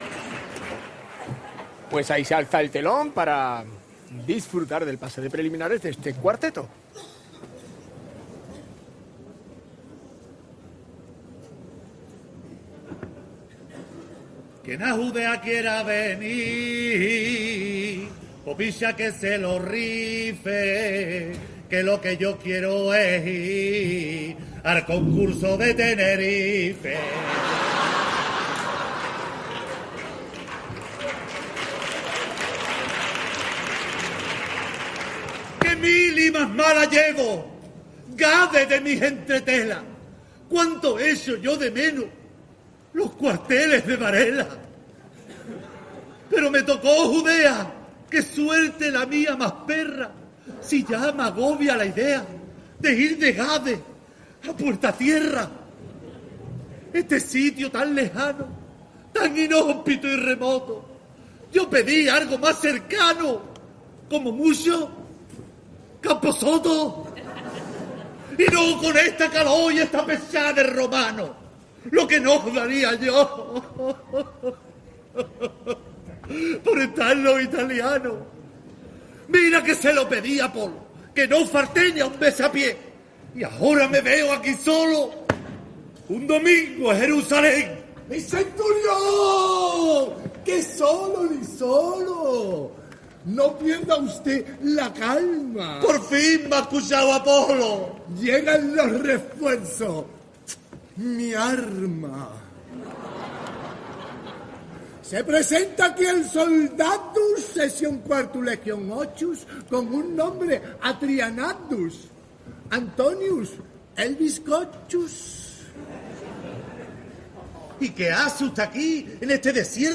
Cuarteto Crónica de una muerte más que anunciada - Preliminares - COAC MP3
Disfruta de la actuación brindada por la Cuarteto Crónica de una muerte más que anunciada en la fase preliminares del COAC Carnaval de Cádiz 2026.